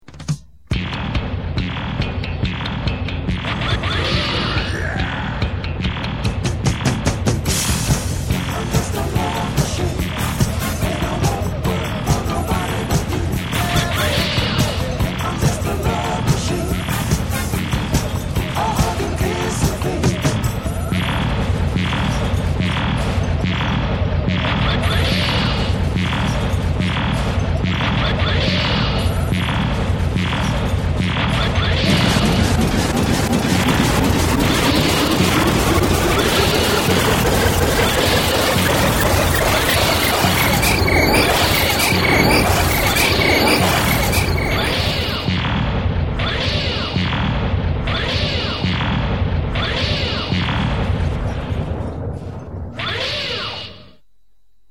The THUMPING! The THUMPING!